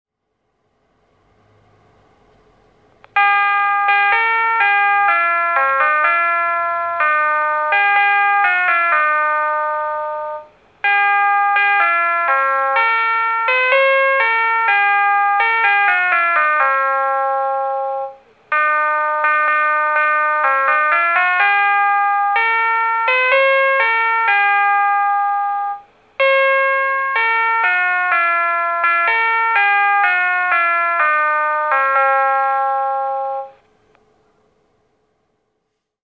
沼津駅前ロータリー(静岡県沼津市)の音響信号を紹介しています。